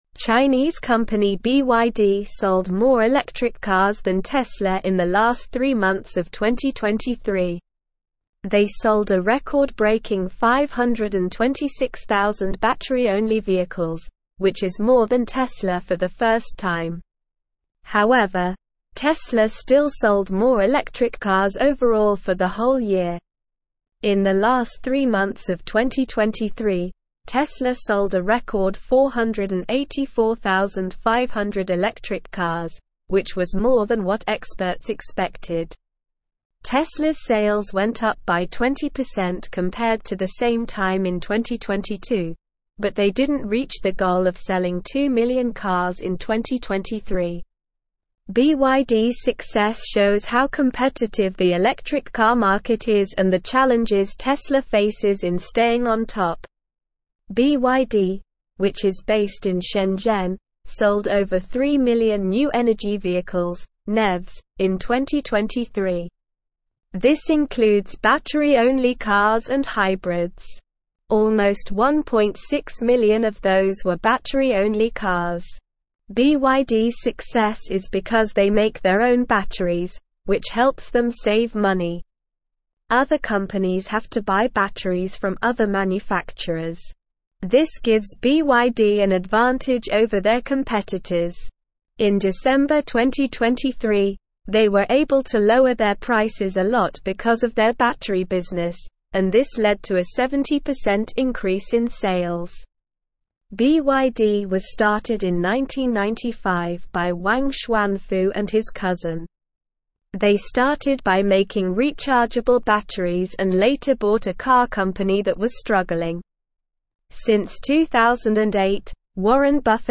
Slow
English-Newsroom-Upper-Intermediate-SLOW-Reading-BYD-Overtakes-Tesla-in-Electric-Vehicle-Sales.mp3